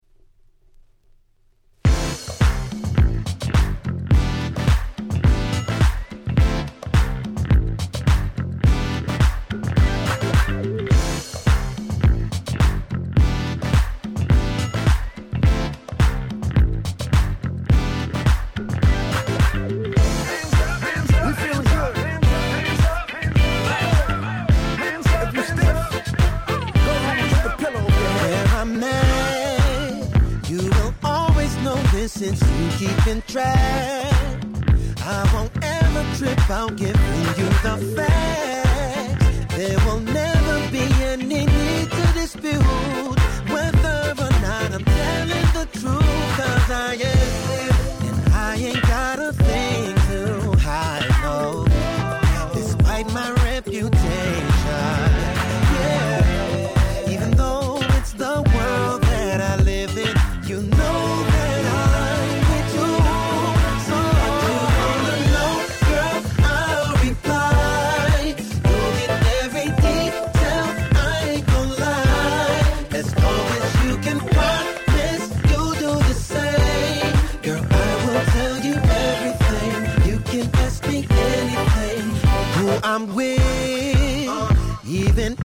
11' Very Nice R&B !!